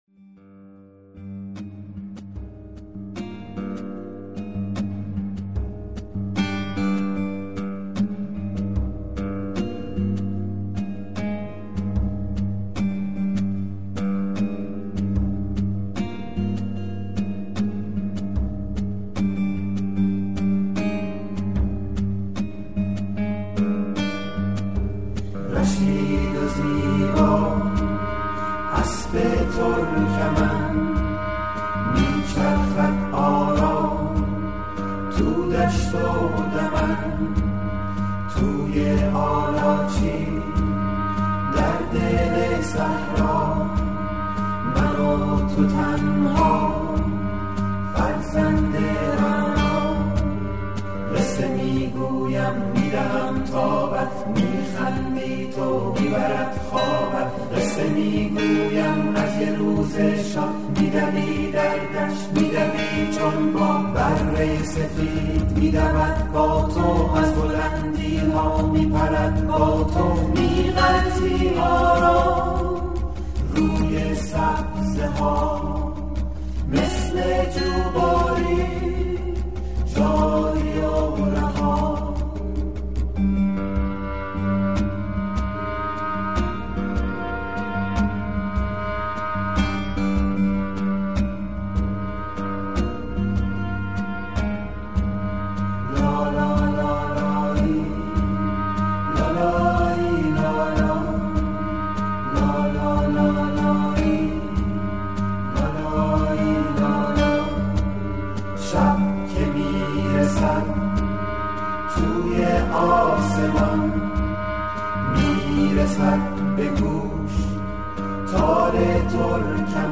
لالایی
آهنگ لالایی